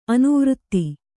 ♪ anuvřtti